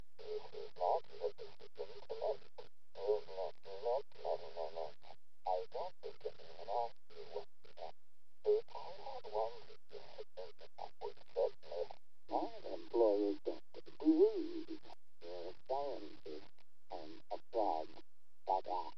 The second Half-Life G-Man sighting is accompanied by muffled conversation.
Also, these are interpretations as to what is said - the sound files aren't very clear!